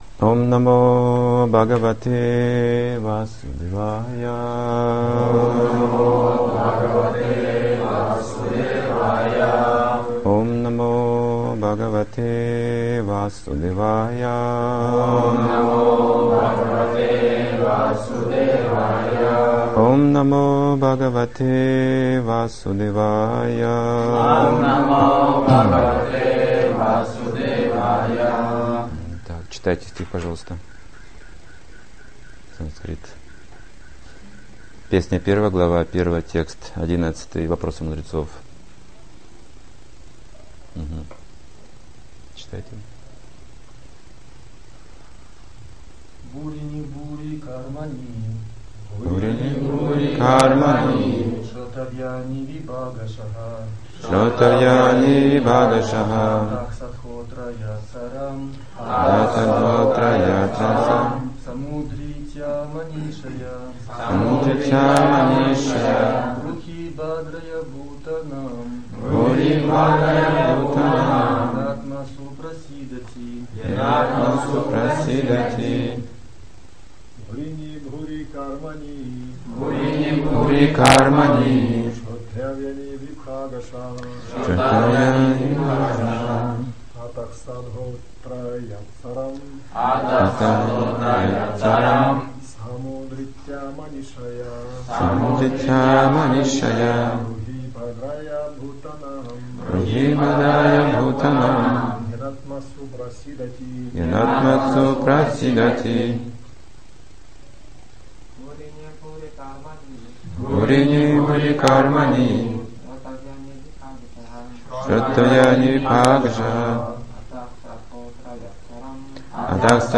Темы, затронутые в лекции